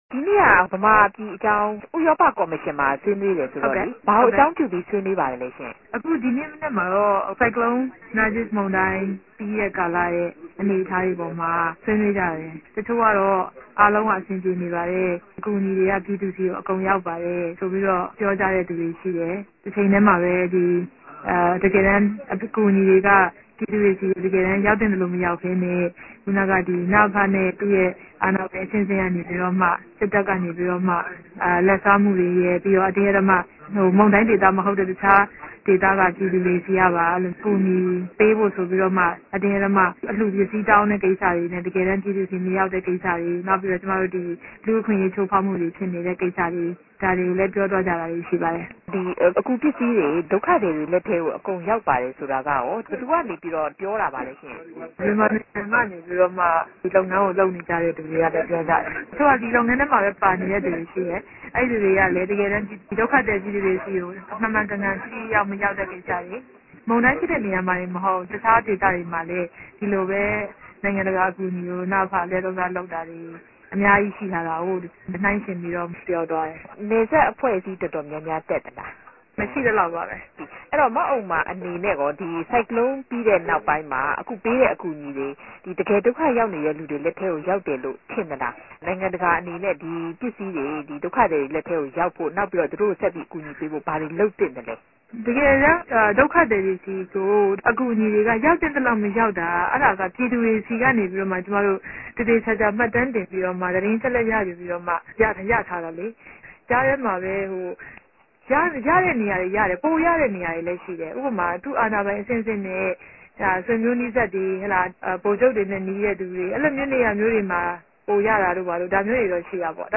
ဆက်သြယ်ဆြေးေိံြးခန်း။